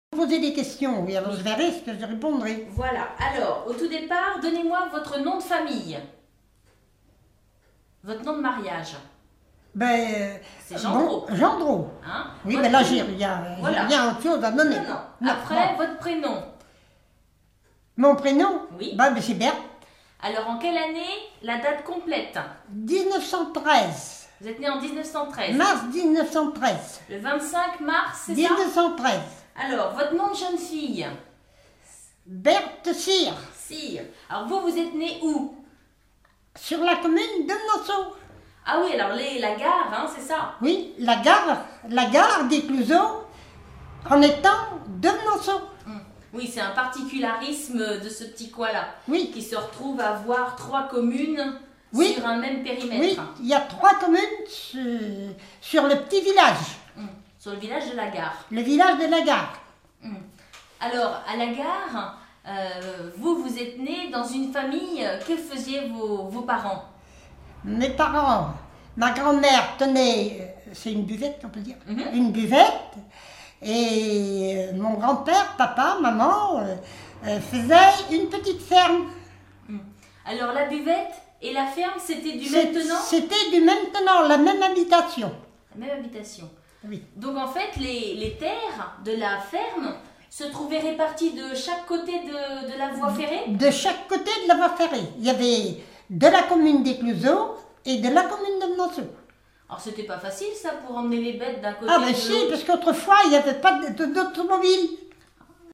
Témoignages sur la vie domestique